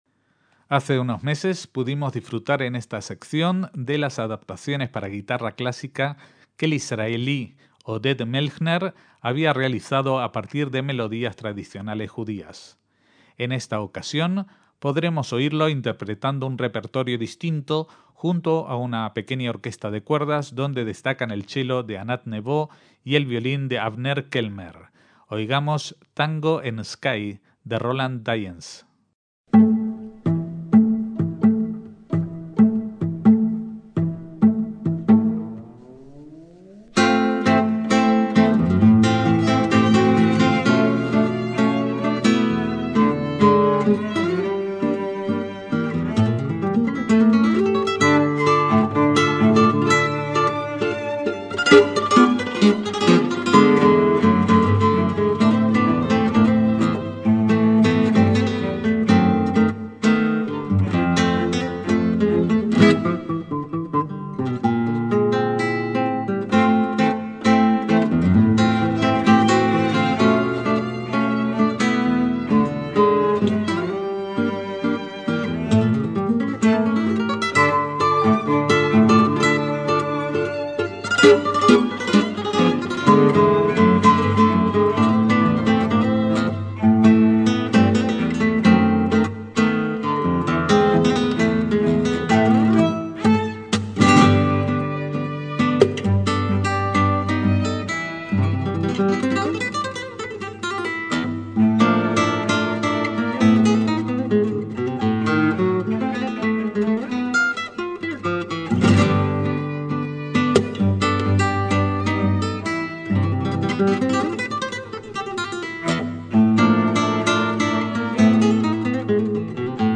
MÚSICA CLÁSICA
guitarra clásica y flamenca
música latina
arreglos de música popular de América del Sur y España